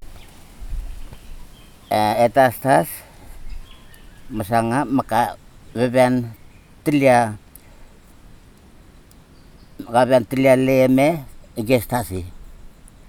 digital wav file recorded at 44.1 kHz/16 bit on Marantz PMD 620 recorder
Sesivi, Ambrym, Vanuatu